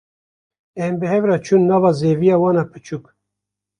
Pronunciado como (IPA)
/t͡ʃuːn/